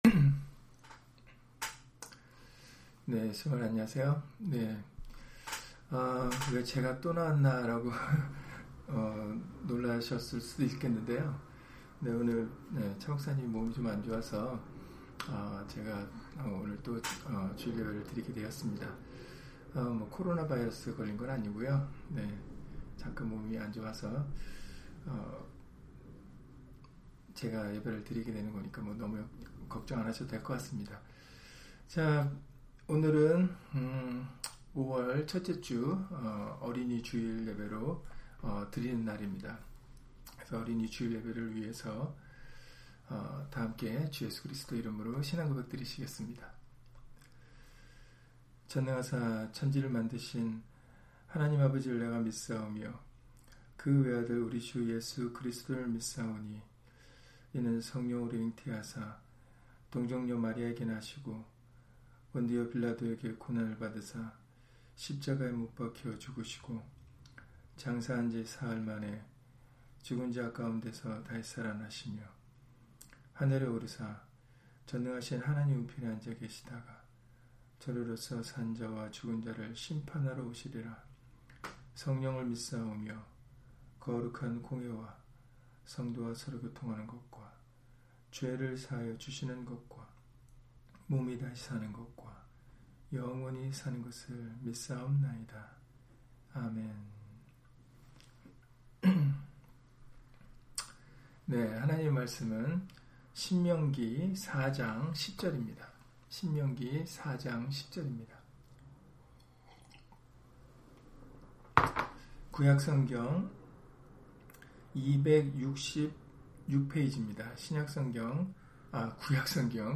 신명기 4장 10절 [어린이 주일] - 주일/수요예배 설교 - 주 예수 그리스도 이름 예배당